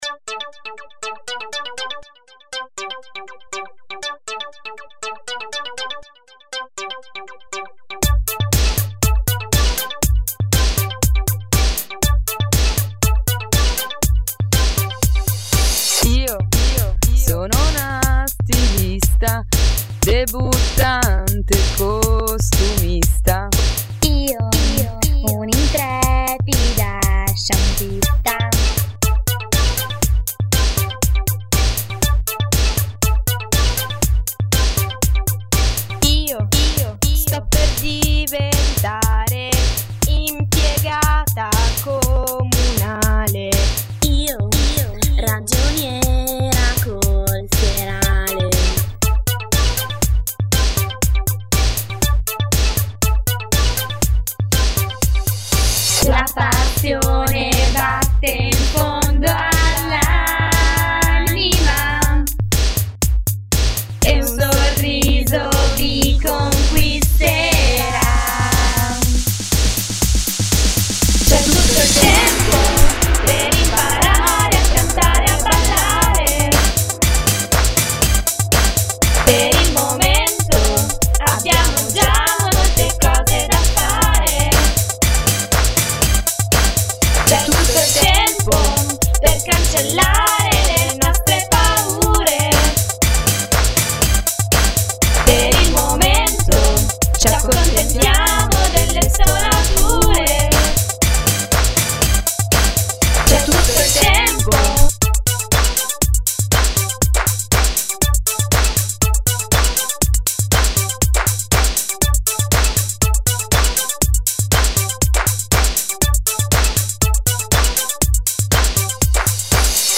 a colpi di filo spinato vocale